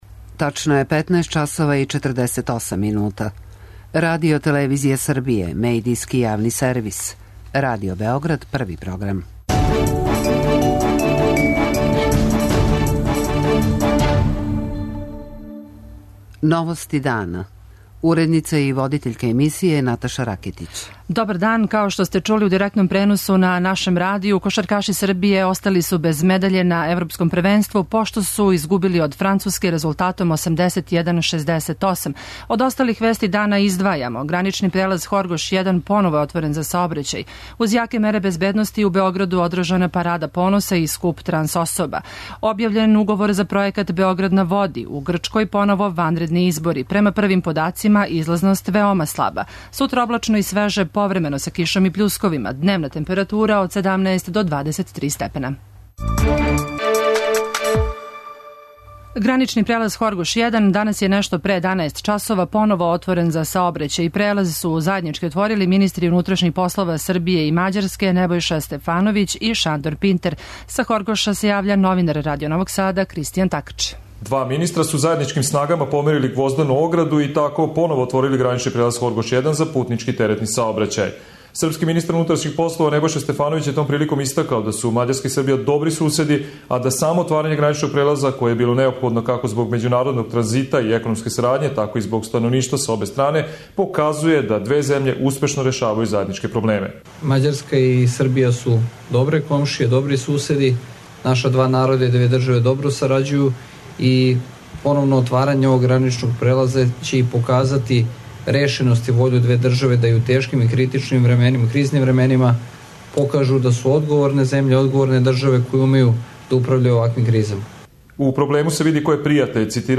У Новостима дана пратимо најважније догађаје у земљи и свету о којима извештавају наши репортери.